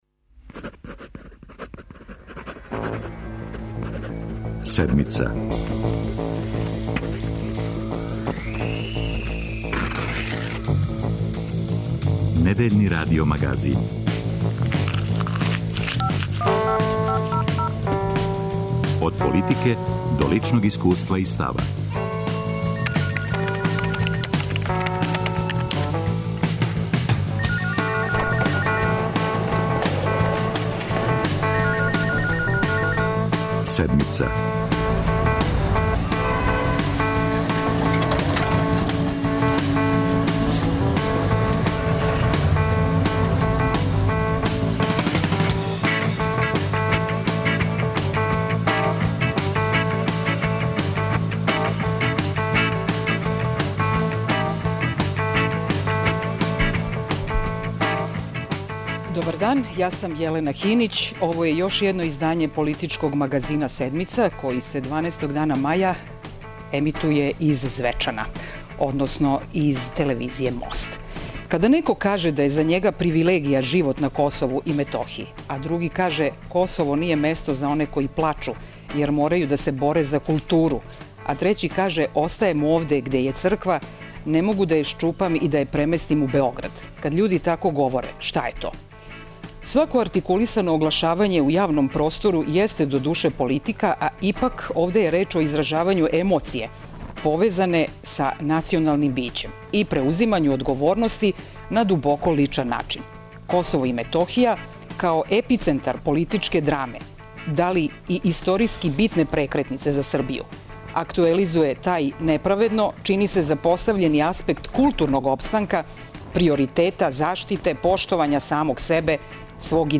Екипа Седмице ове недеље је у Звечану. Шта је повод за неспоразум у споразуму Београд-Приштина? Јесу ли угрожени етнос, култура, биће и идентитет Срба на Косову и Метохији?